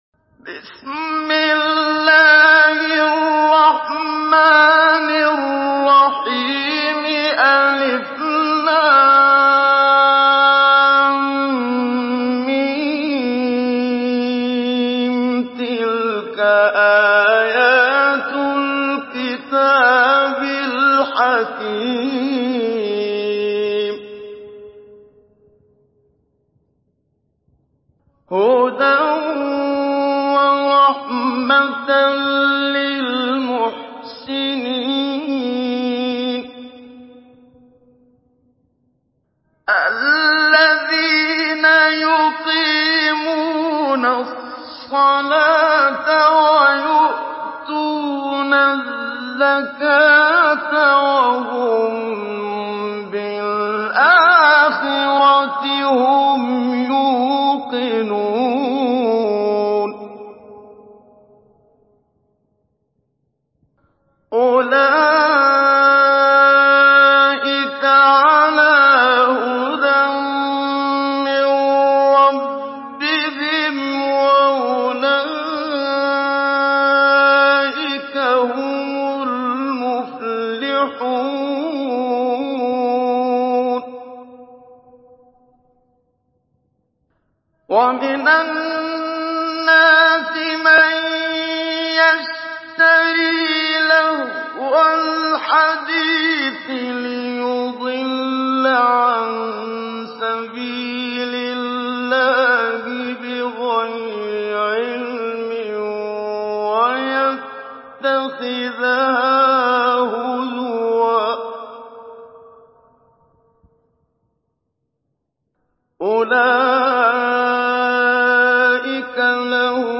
سورة لقمان MP3 بصوت محمد صديق المنشاوي مجود برواية حفص